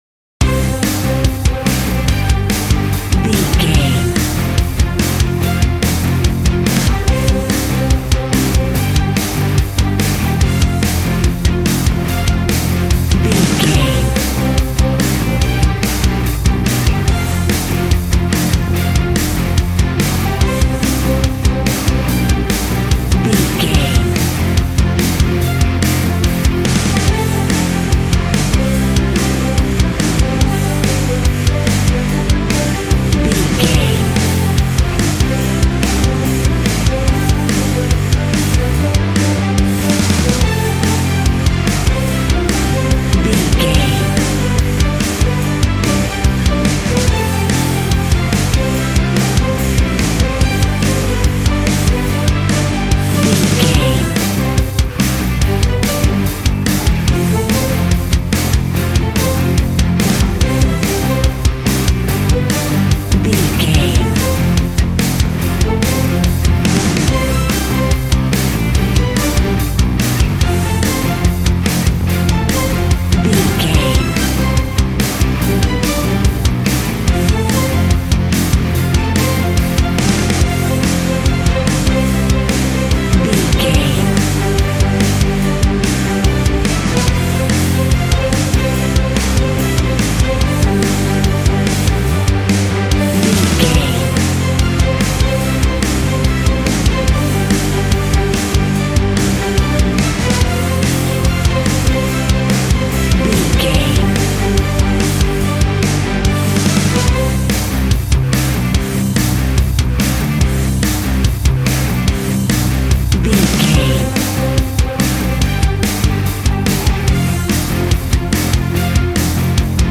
Ionian/Major
angry
heavy
aggressive
electric guitar
drums
bass guitar